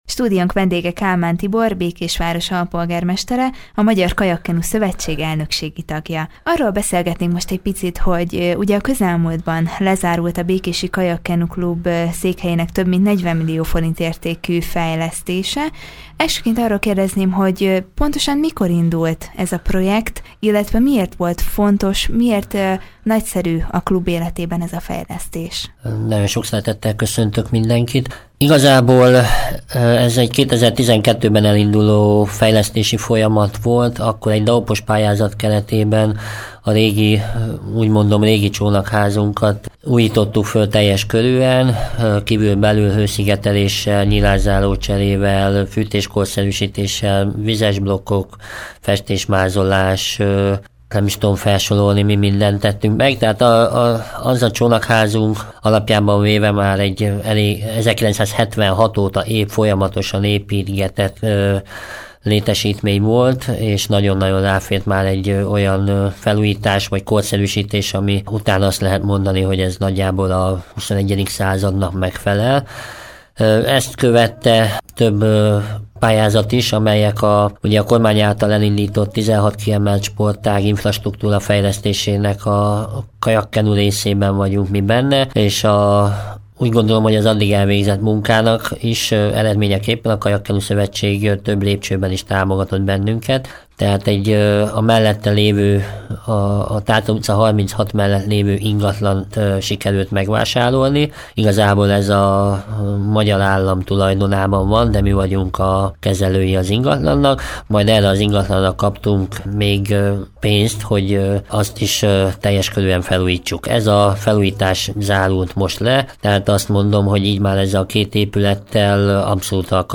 Végéhez ért a Békési Kajak-Kenu Club több mint 40 millió forint értékű fejlesztése, ezzel egy hosszú folyamat ért véget. Erről beszélgetett tudósítónk Kálmán Tiborral, Békés Város alpolgármesterével, a Magyar Kajak-Kenu Szövetség elnökségi tagjával.